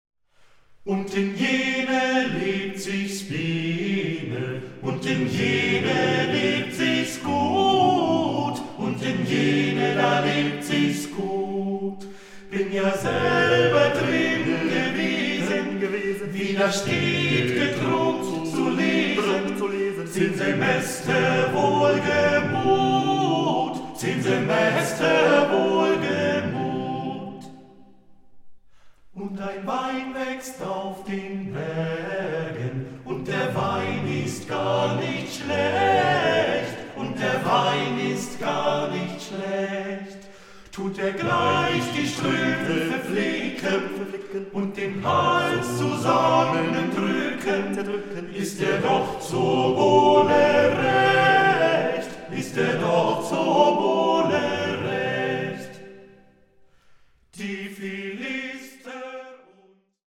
the eight singers revive an entire folk culture!